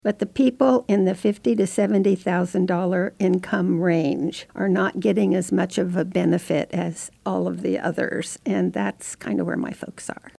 Rep. Carlin and Rep. Dodson appeared on KMAN’s In Focus Friday to recap the busy week in the Kansas Legislature.